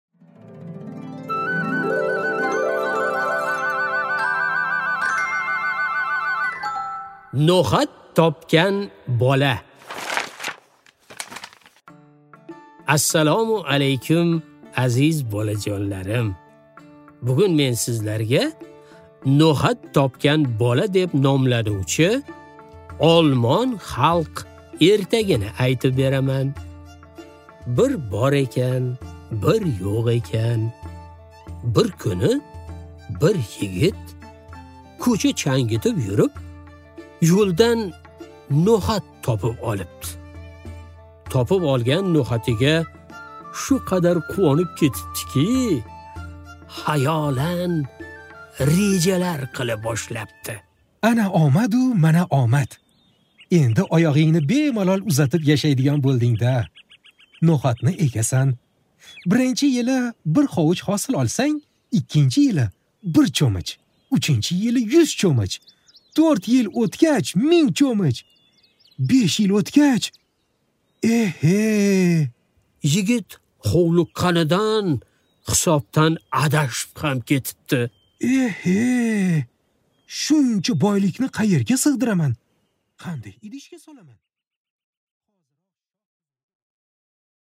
Аудиокнига No'xat topgan bola